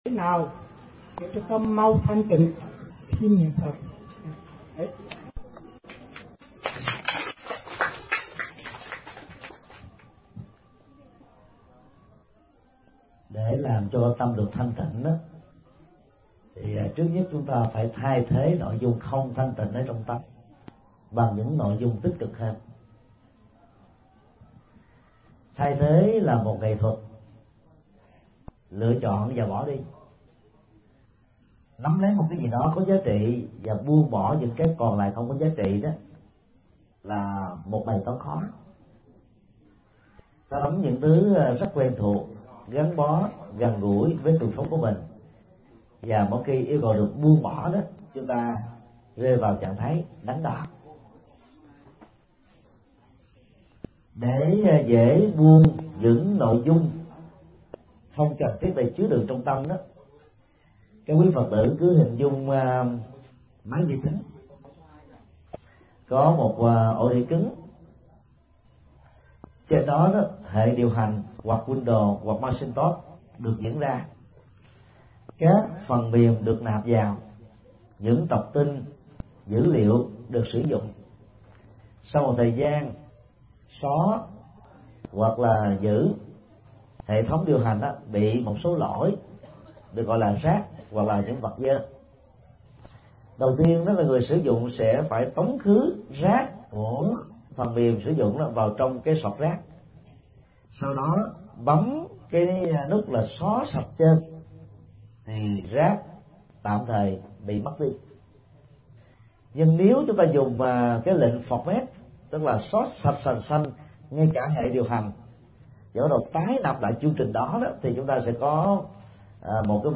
Vấn đáp: Hướng dẫn giúp tâm thanh tịnh – Thích Nhật Từ